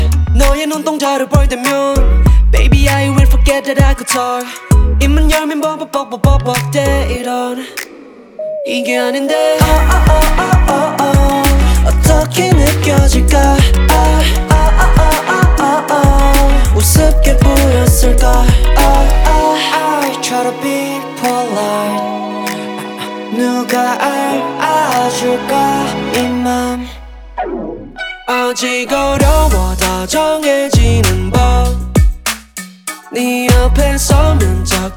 K-Pop Pop
2025-06-27 Жанр: Поп музыка Длительность